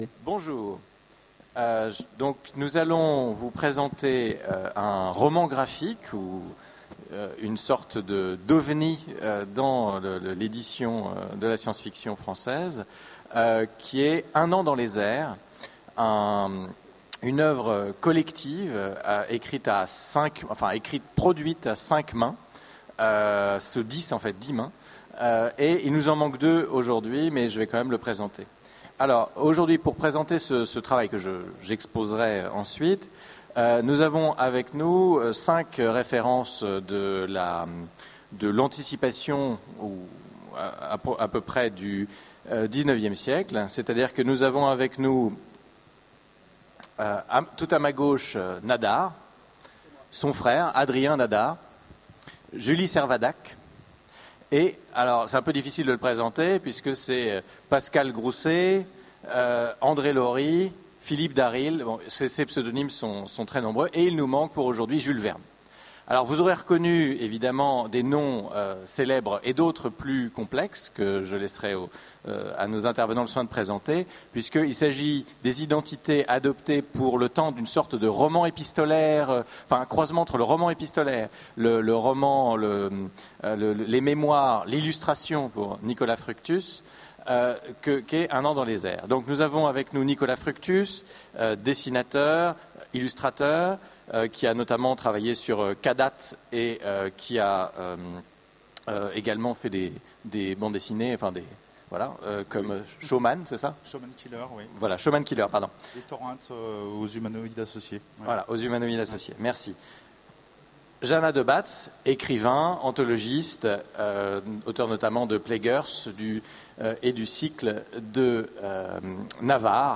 Utopiales 13 : Conférence Un an dans les airs avec Jules Verne